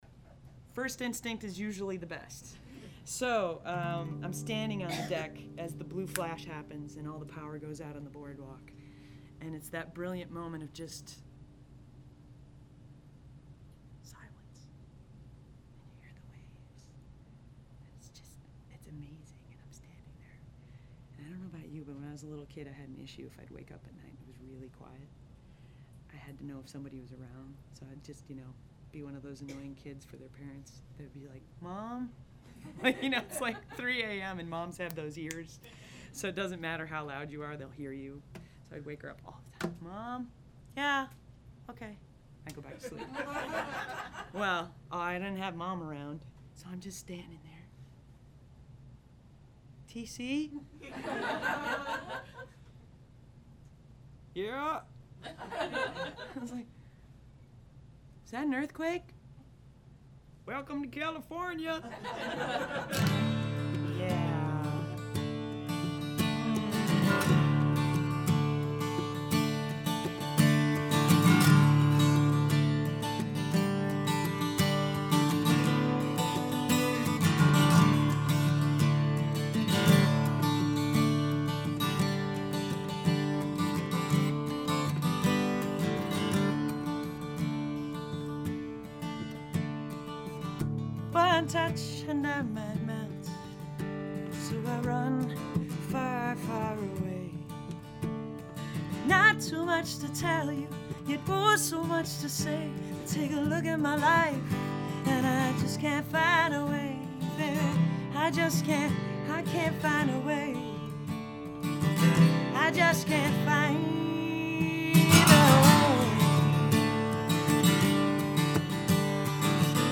I was pretty light on the NR - This is a very dynamic recording and it's going to stay that way.
FWIW, the noise sample was taken right after the "blue flash" - I notched the ringing strings out of the noise profile sample.
mixed_no_nr_dry.mp3